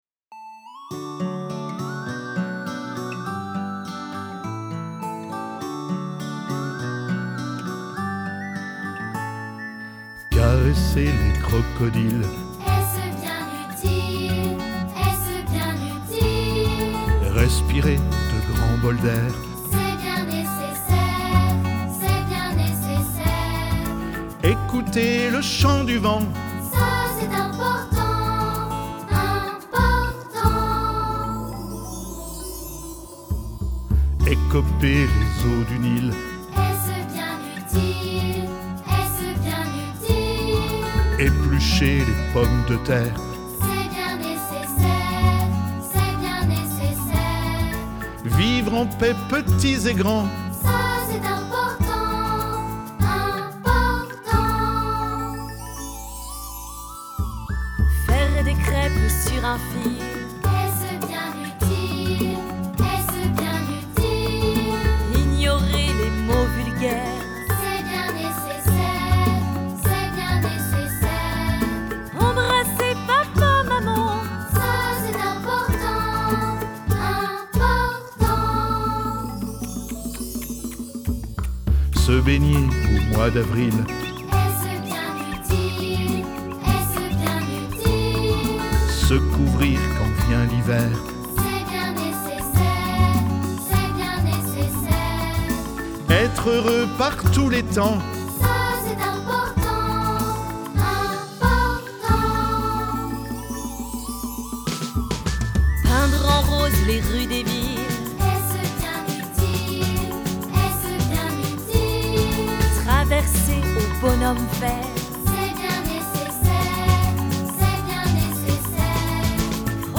Version chantée (mp3)